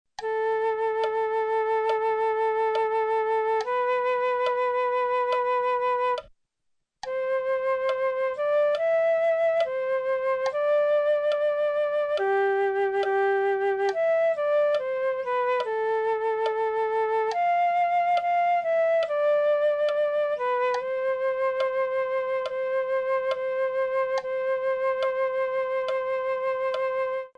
Il risultato è che si sommano i valori delle note legate e i suoni "si fondono" in un'unica espressione acustica.
Osserviamo: legature.mp3 L'ascolto dimostra che le note legate vengono eseguite senza separazione , come se si trattasse di un unico suono .